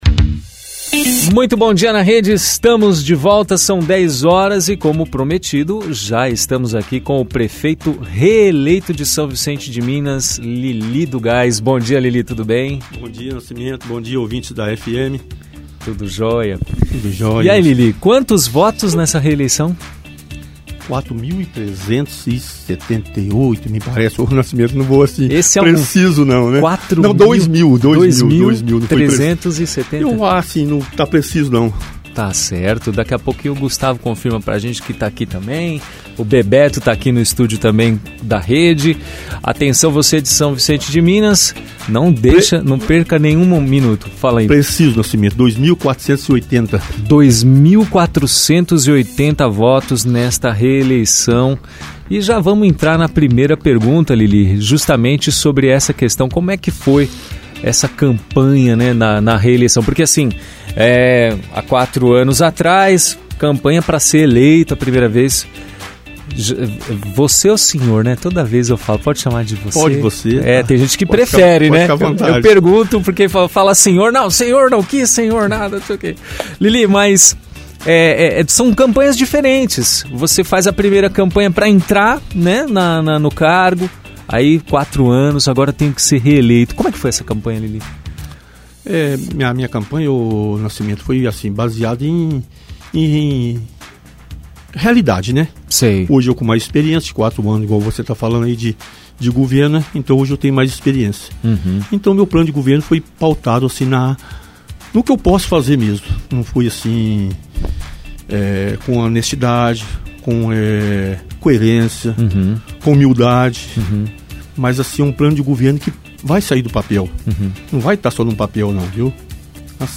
Entrevista com o Prefeito reeleito em São Vicente de Minas Lili do Gás - Rádio Rede FM